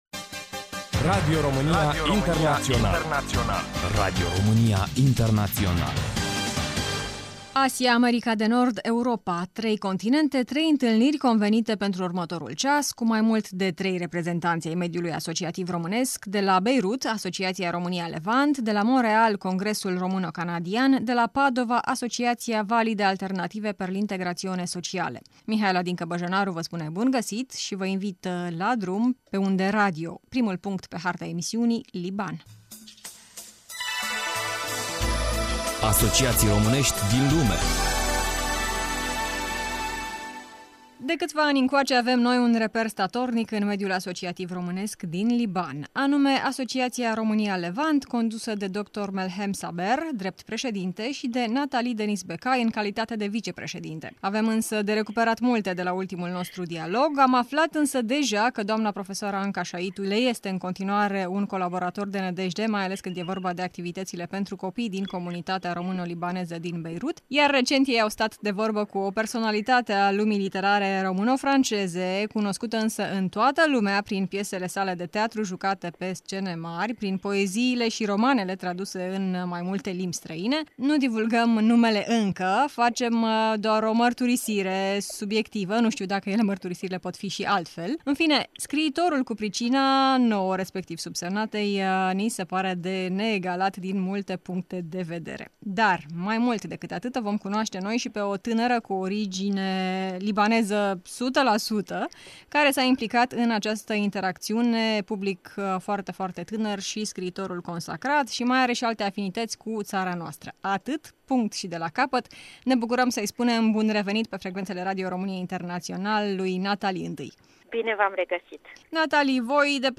Interviu la Radio Romania International Romania Levant Association – Lebanon